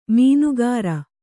♪ mīnugara